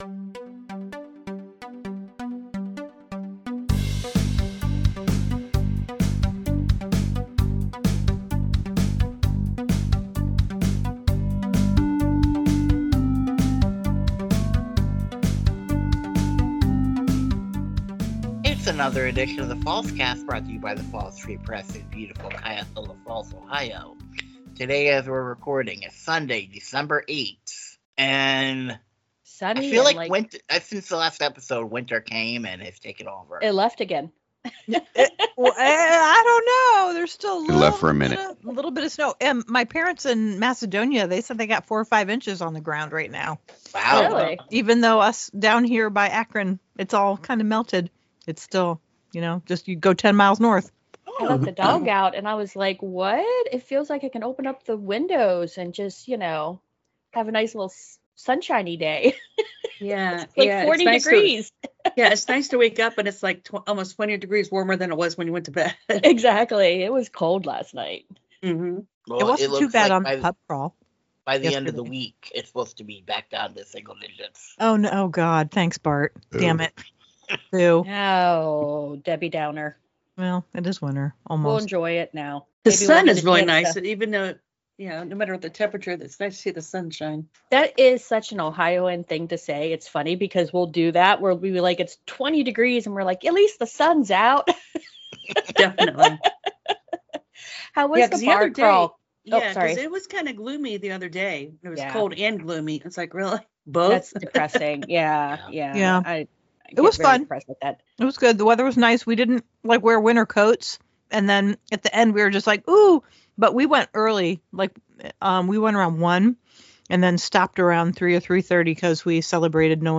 Fallscast theme and interstitial